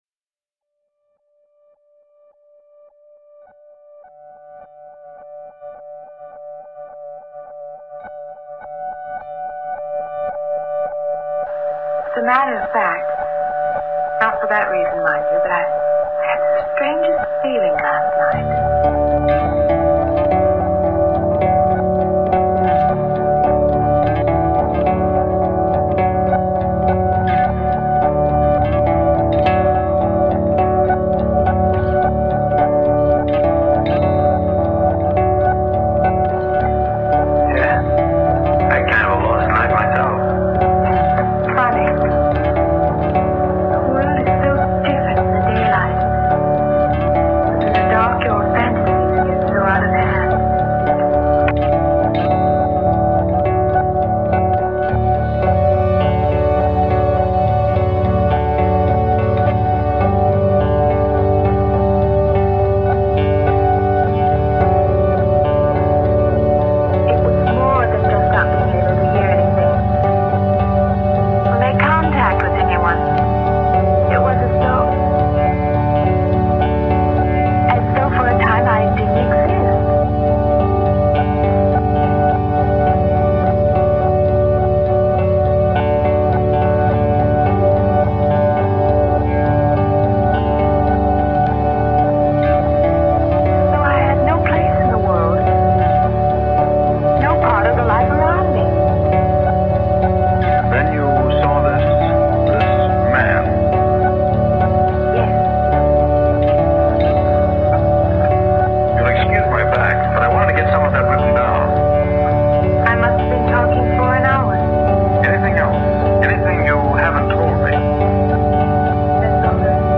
French post-rock band
instrumental yet sample laden